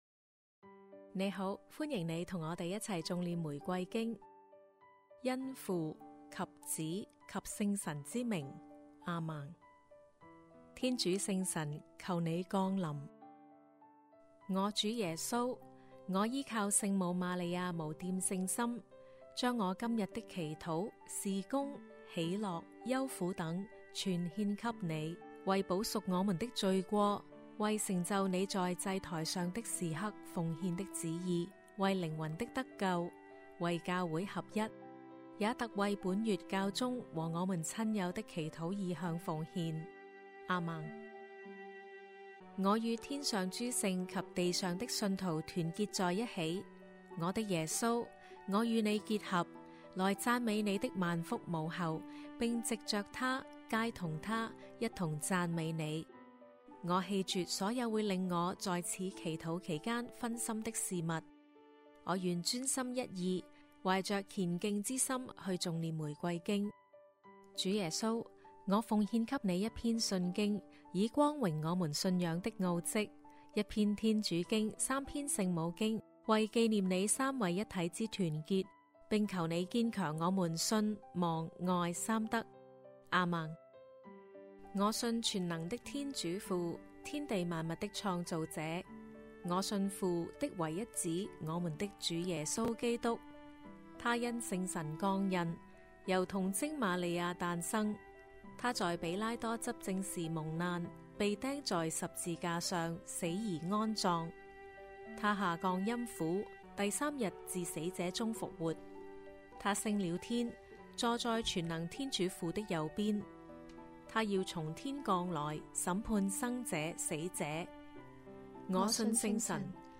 童聲齊頌玫瑰經：榮福五端*